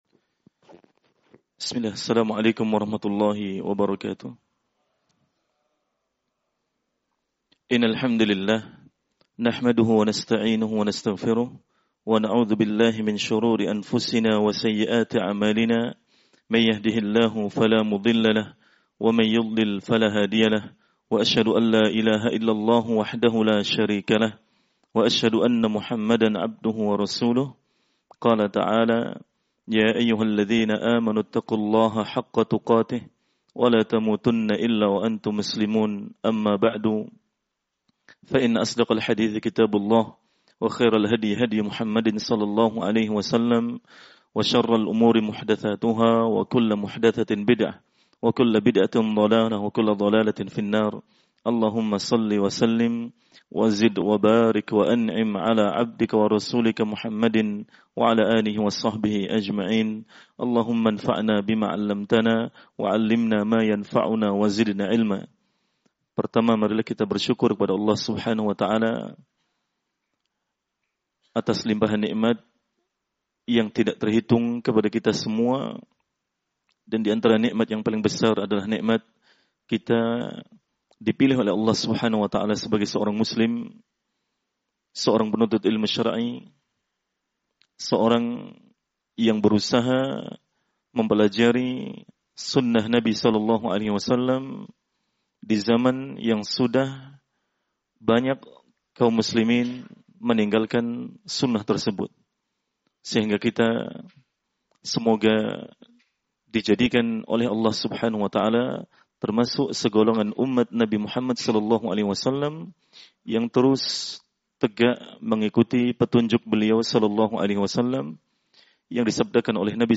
Setelah memuji Allâh dan bershalawat atas Nabi ﷺ Ustadz mengawali kajian dengan mengingatkan kita untuk selalu bersyukur atas nikmat Sunnah, hingga saat ini kita masih termasuk golongan yang mengikuti kebenaran.